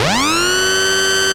BWB 6 SOUND (SIZZLE UP).wav